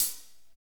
HAT P B C04L.wav